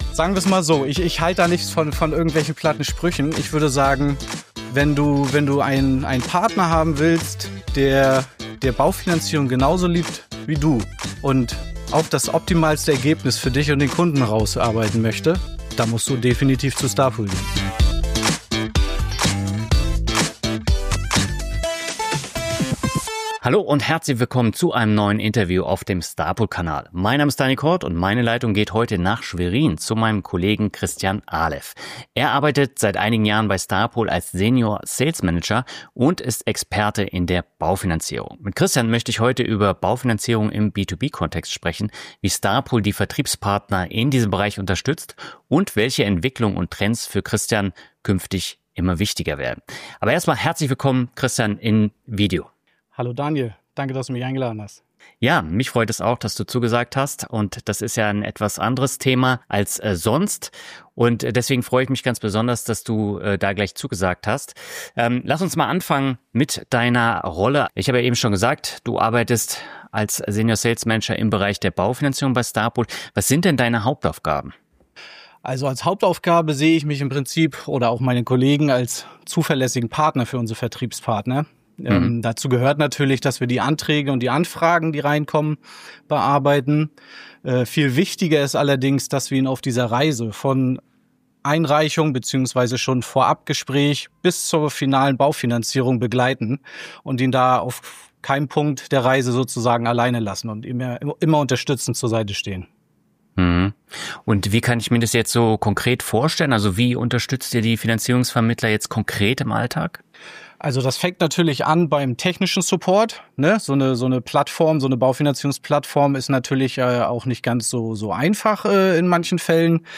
Baufinanzierung im Vermittleralltag: Wie starpool Vertriebspartner konkret unterstützt- Interview